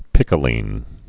(pĭkə-lēn, pīkə-)